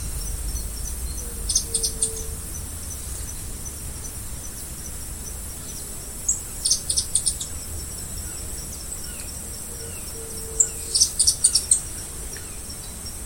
White-crested Tyrannulet (Serpophaga subcristata)
Life Stage: Adult
Location or protected area: Reserva Natural del Pilar
Condition: Wild
Certainty: Recorded vocal